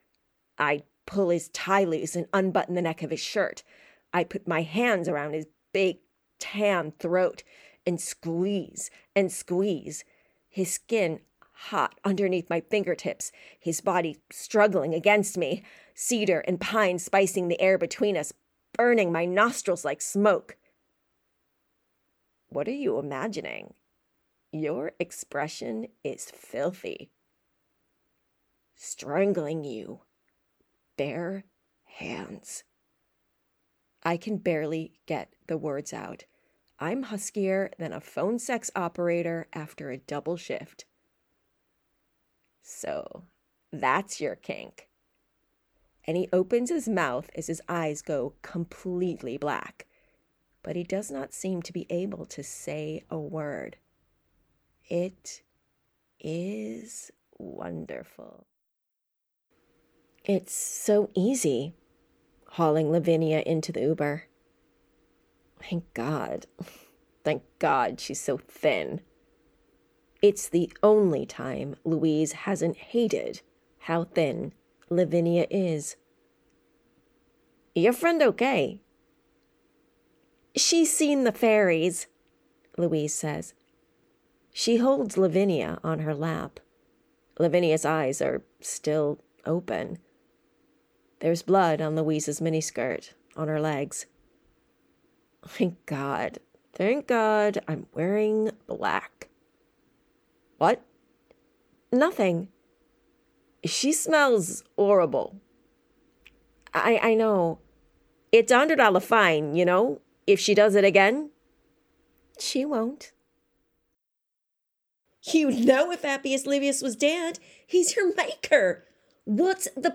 Audiobook Reel
Playing age: Teens - 20s, 20 - 30sNative Accent: AmericanOther Accents: American, RP
• Native Accent: American-Midwest
Her refined accent skills, honed through work in LA, Ireland, and the UK, lend authenticity and depth to every performance, making her a unique, compelling and dynamic voice actor.